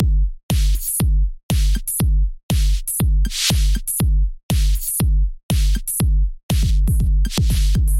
Electro House Beat 120
描述：admau5ian Electrobeat
标签： 120 bpm Deep House Loops Drum Loops 1.35 MB wav Key : Unknown
声道立体声